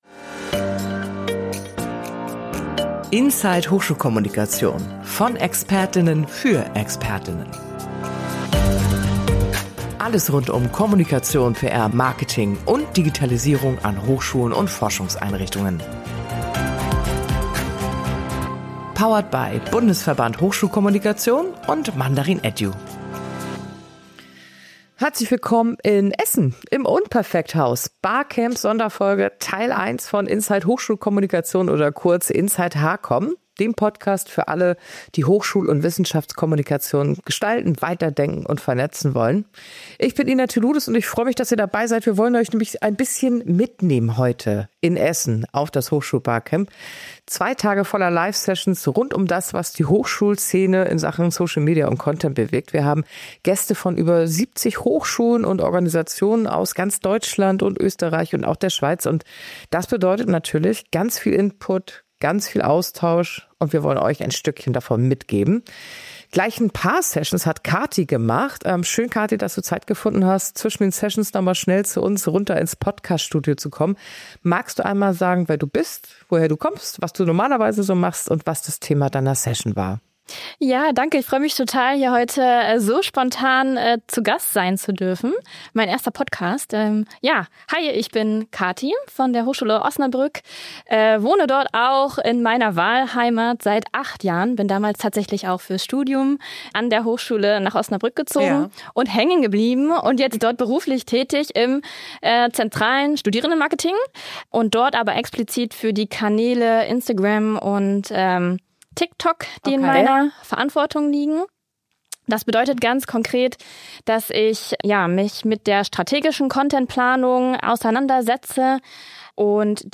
Beschreibung vor 1 Monat Live vom Hochschul-Barcamp in Essen: In dieser Sonderfolge von Inside Hochschulkommunikation nehmen wir euch mit ins Unperfekthaus – mitten hinein in zwei Sessions, die zeigen, wie sehr sich Social Media in der Hochschulwelt gerade verändert. Was funktioniert auf TikTok – wenn nicht nur Memes und Trends? Und warum werden Kommentare plötzlich zur eigenen Disziplin?